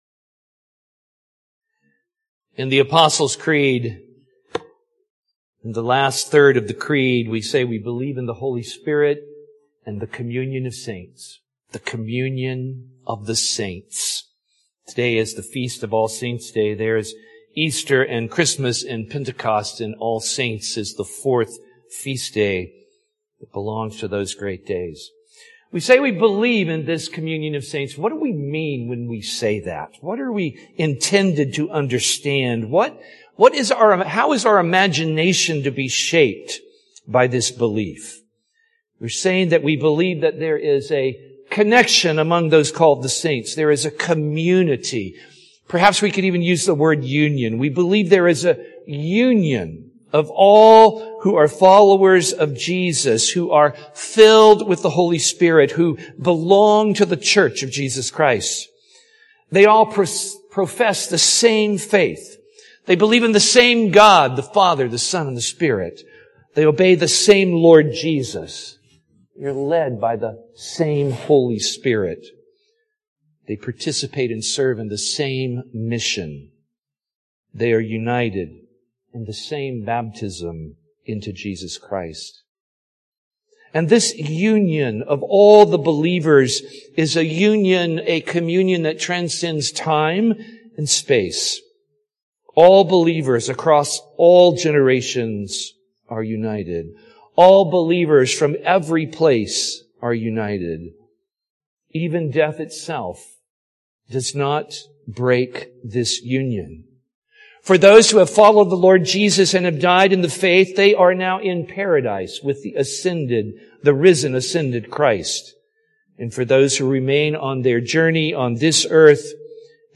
Posted on Nov 9, 2022 in Sermons, Worship |